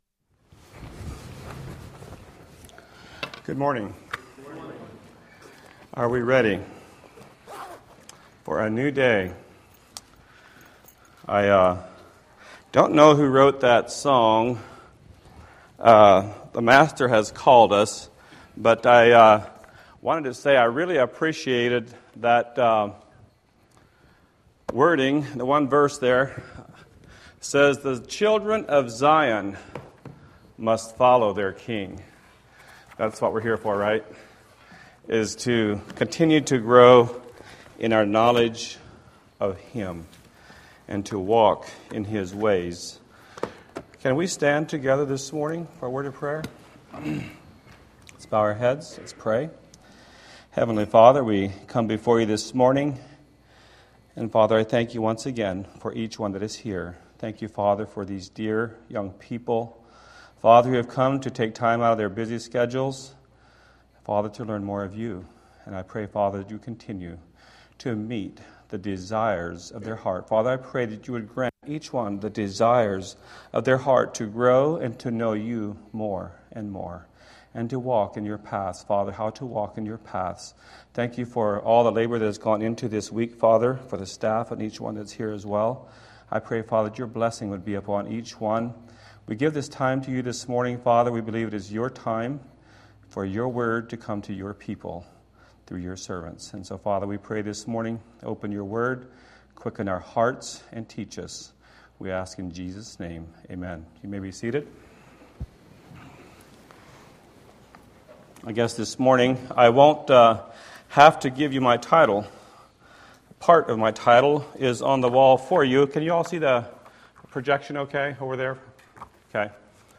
Series: Navigating A Godless Society; Embracing Godly Convictions, Youth Bible School 2021